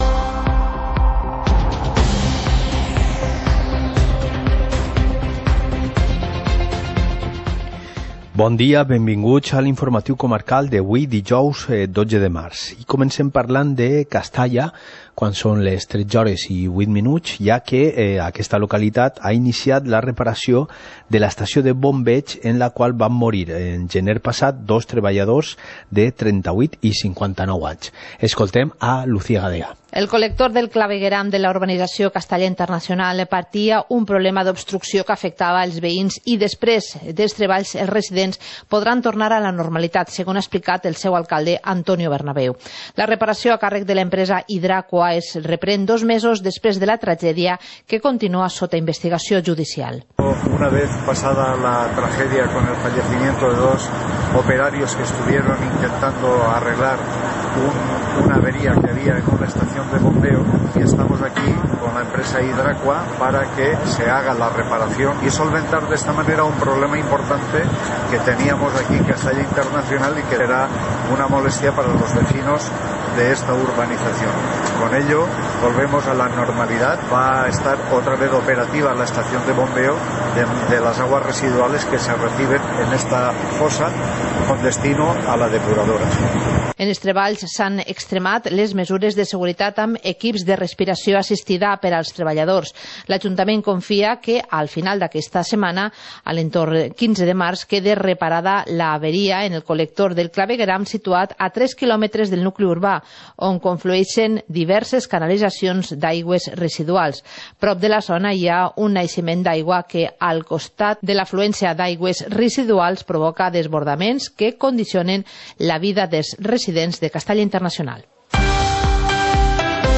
Informativo comarcal - jueves, 12 de marzo de 2020